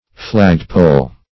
flagpole \flag"pole`\ (fl[a^]g"p[=o]l`), n.